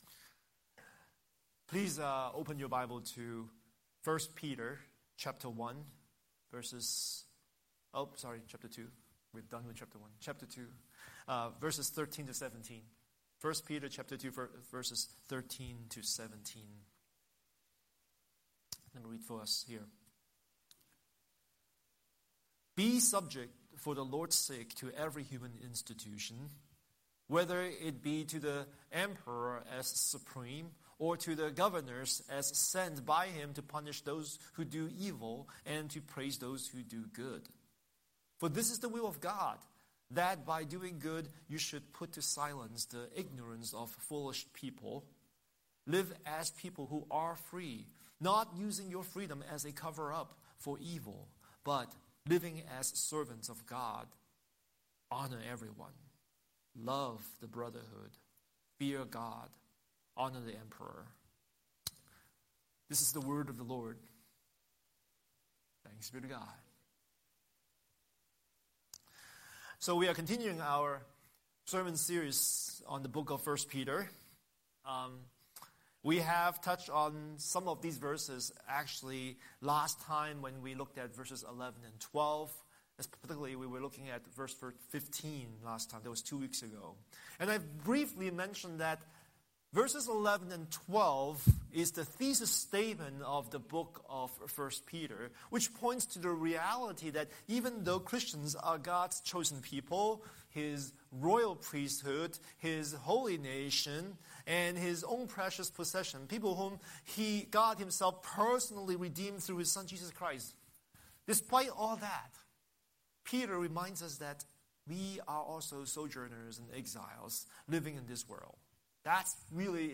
Scripture: 1 Peter 2:13–17 Series: Sunday Sermon